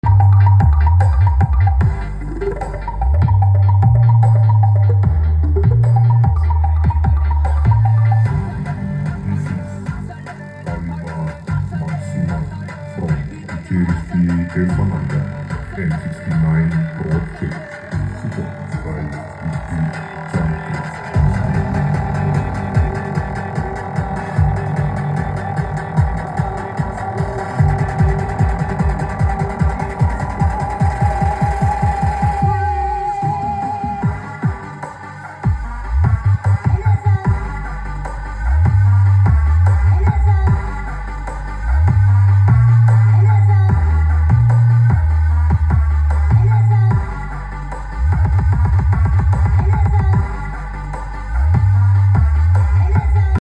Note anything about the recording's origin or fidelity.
Cek sound power clas h plus planar 115 isi ashley 15v400